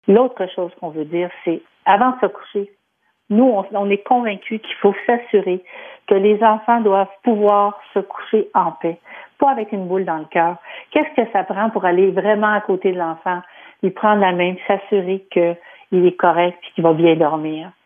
entrevistó